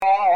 Free MP3 vintage Sequential circuits Pro-600 loops & sound effects 4